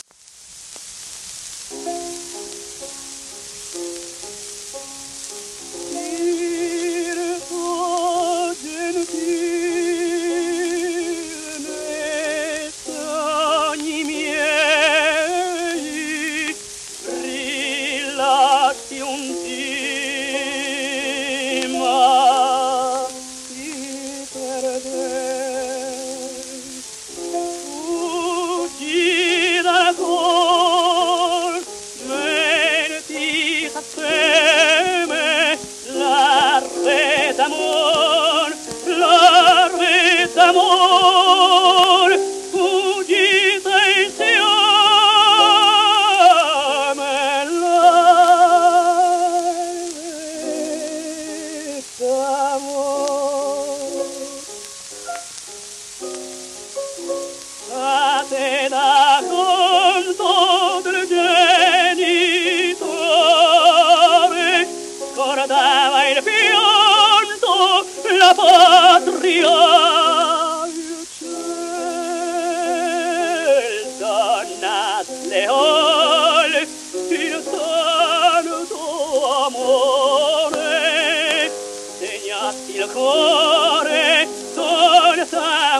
w/ピアノ
旧 旧吹込みの略、電気録音以前の機械式録音盤（ラッパ吹込み）